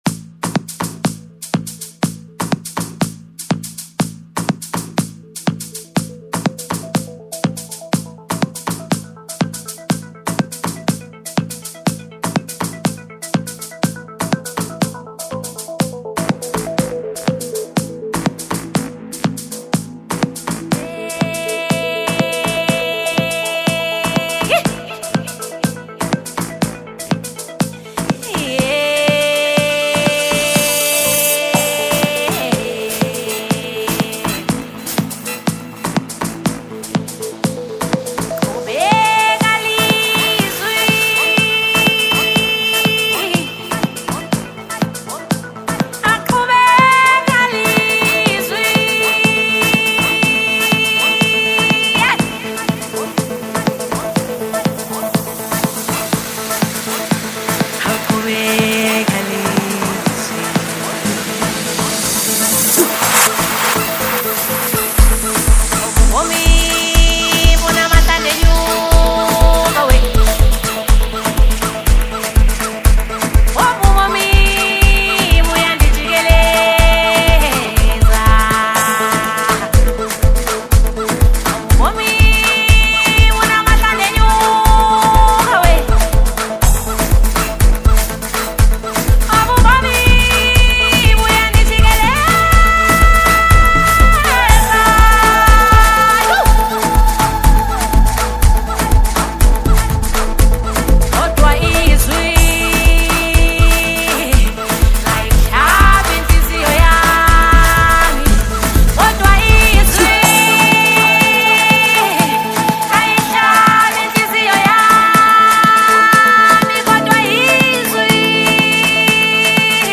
enchanting vocals